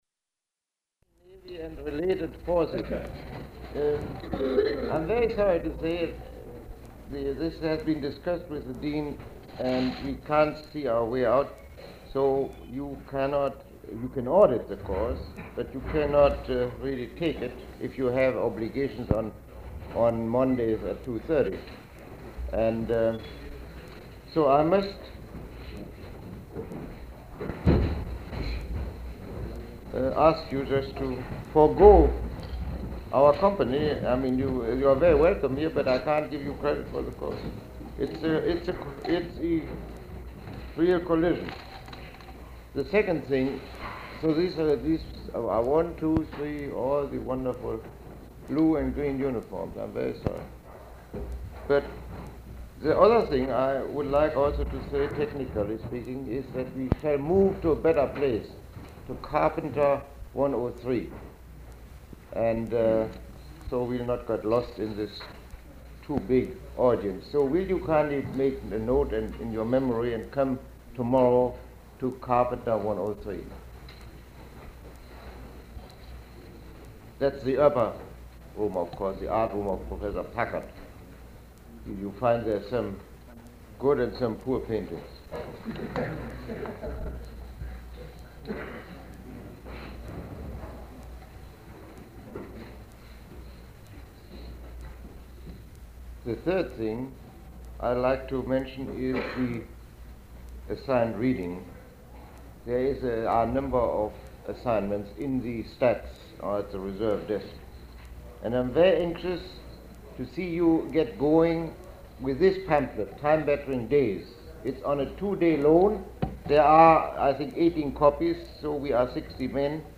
Lecture 01